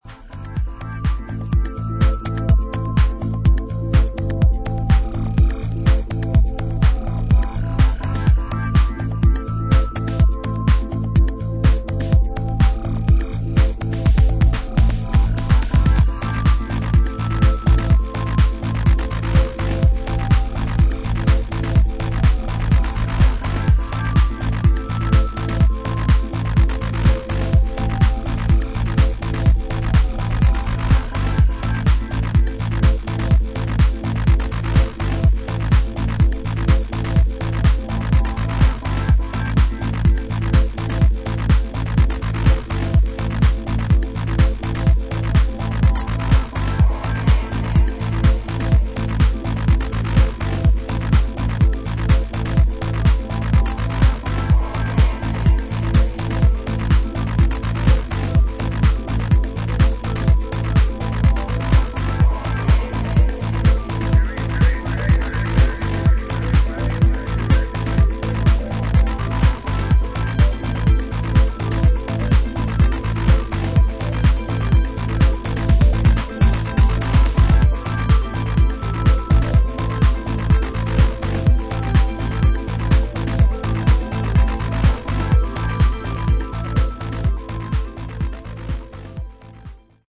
チリノイズあり。